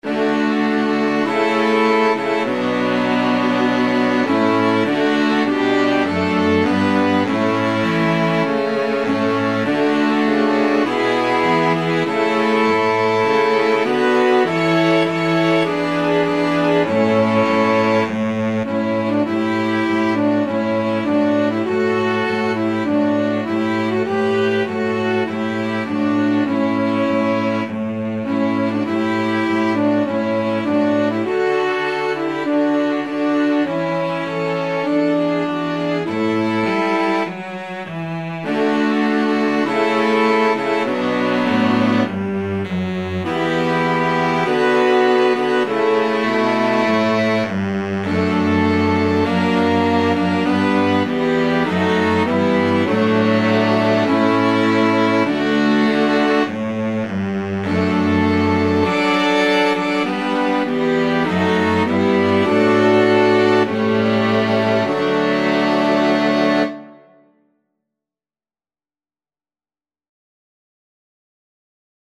TrumpetViolin
Alto SaxophoneClarinetViolin
Tenor SaxophoneViolaFrench Horn
Baritone SaxophoneCello
4/4 (View more 4/4 Music)
Maestoso risoluto